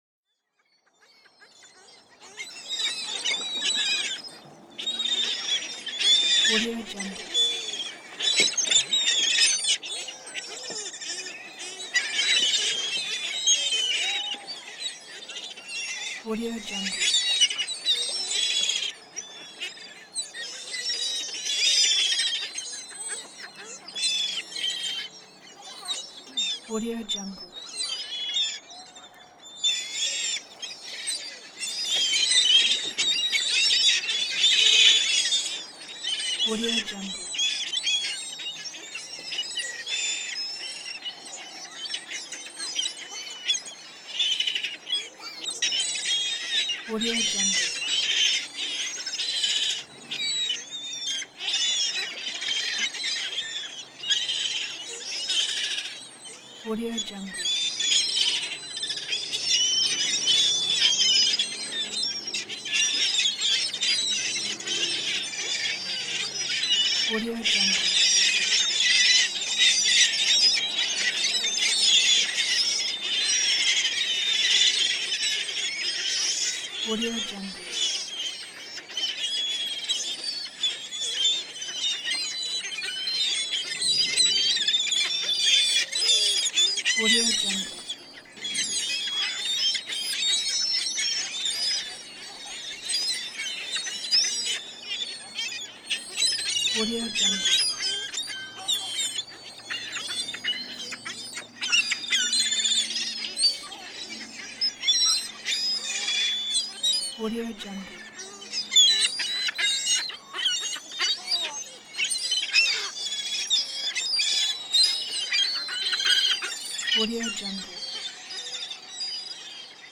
دانلود افکت صوتی آمبیانس مرغ های دریایی در ساحل
Seagull Ambience royalty free audio track is a great option for any project that requires nature sounds and other aspects such as an ambience, atmosphere and background.
Sample rate 16-Bit Stereo, 44.1 kHz
Looped No